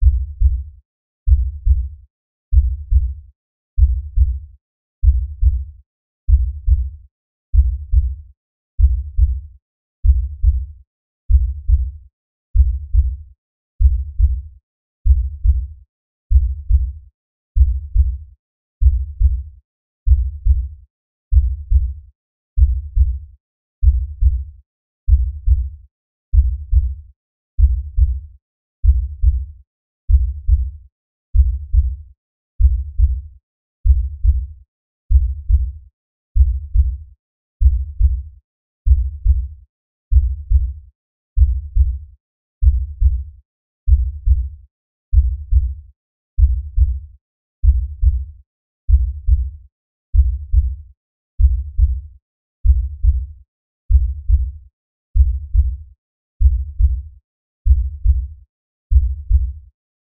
Звуки низкой частоты
Глубокие басы, инфразвук и резонансные волны могут вызывать неоднозначные ощущения — от легкого дискомфорта до сильной усталости.
Низкочастотные звуки для соседей сверху включайте на повтор и уходите из дома